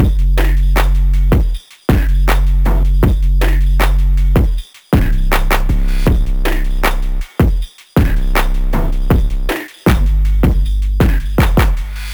• Dirty Phonk Saturated Drum Loop Gm 158 bpm.wav
Hard punchy kick sample for Memphis Phonk/ Hip Hop and Trap like sound.